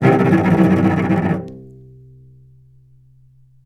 Strings / cello / tremolo / vc_trm-C2-mf.aif
vc_trm-C2-mf.aif